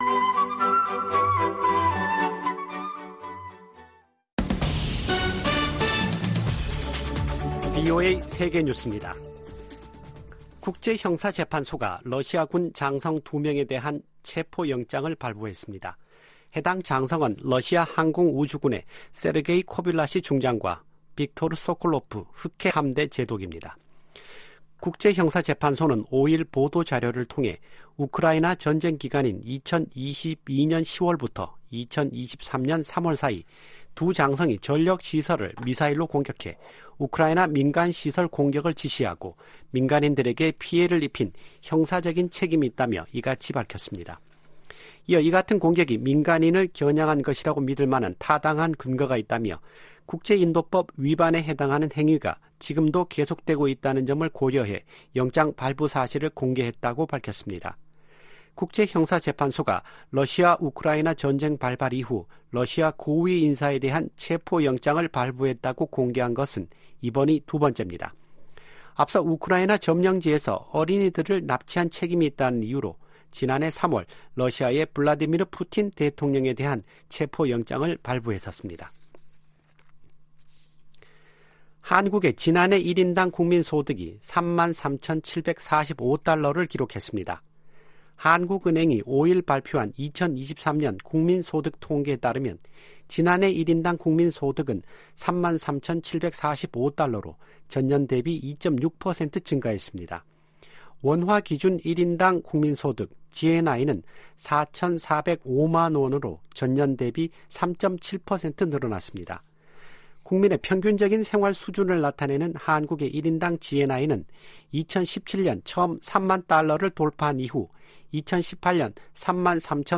VOA 한국어 아침 뉴스 프로그램 '워싱턴 뉴스 광장' 2024년 3월 6일 방송입니다. 북한의 영변 경수로 가동 움직임이 계속 포착되고 있다고 국제원자력기구(IAEA)가 밝혔습니다. 북한에서 철수했던 유럽 국가들의 평양 공관 재가동 움직임에 미국 정부가 환영의 뜻을 밝혔습니다. 북한은 4일 시작된 미한 연합훈련 '프리덤실드(FS)'가 전쟁연습이라고 주장하며 응분의 대가를 치를 것이라고 위협했습니다.